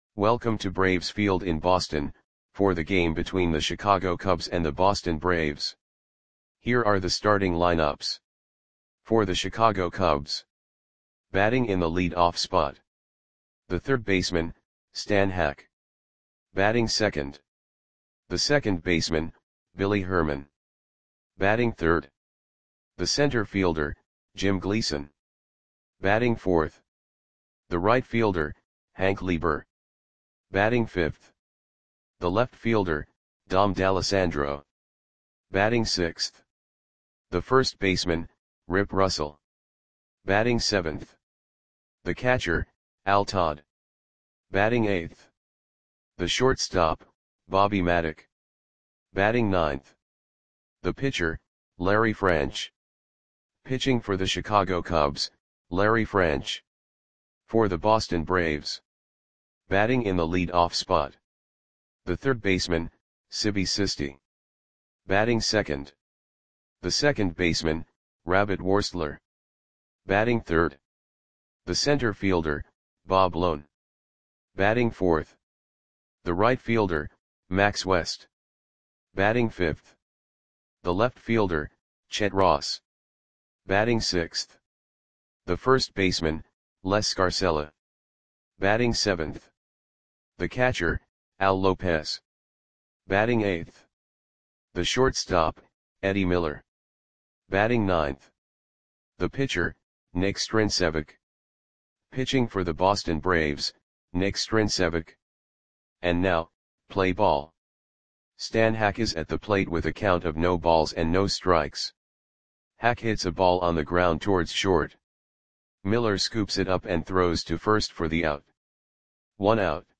Audio Play-by-Play for Boston Braves on May 15, 1940
Click the button below to listen to the audio play-by-play.